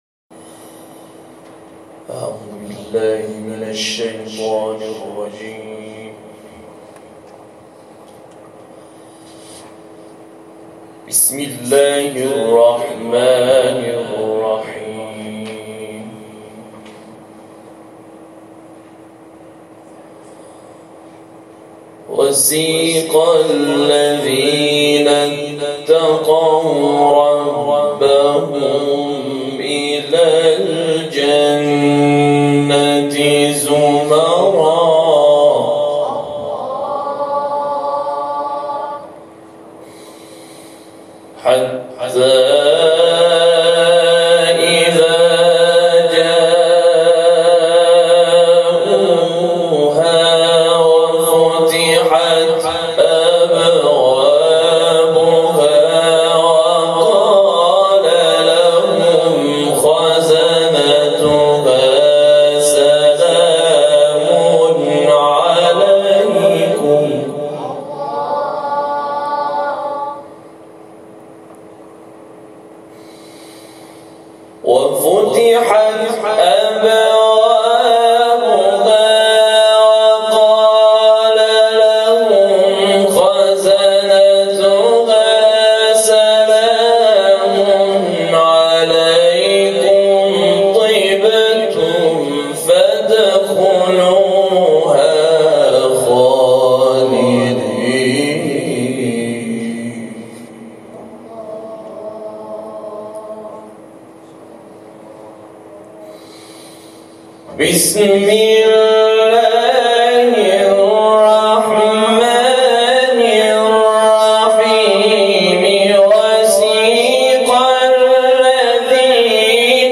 محفل انس با قرآن از سوی مؤسسه اظهرالجمیل الاعلی به مناسبت چهلمین روز شهادت فخری‌زاده برگزار شد.
استخراج صدا - تلاوت